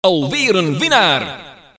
Kermis geluid Alweer een winnaar!
Kermis Geluid Attention
Categorie: Geluidseffecten
geluidseffecten, kermis geluiden